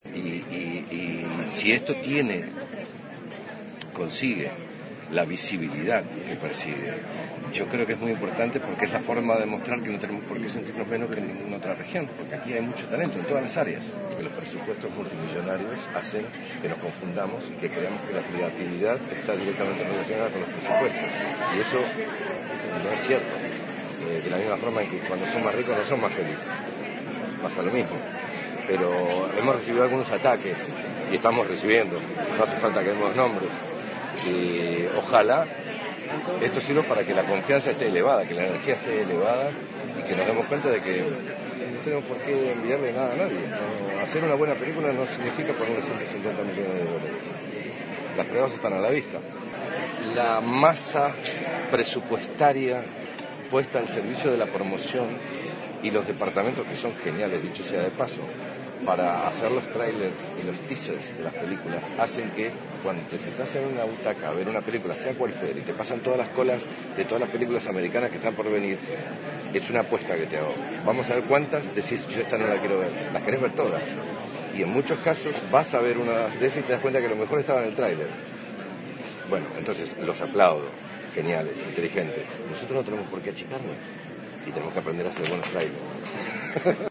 Entrevista
El actor argentino se toma esos halagos con tranquilidad y en su encuentro con El Espectador, en la alfombra roja de los Premios Platino, su discurso apuntó a crear conciencia de la calidad del cine de Iberoamérica.